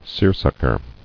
[seer·suck·er]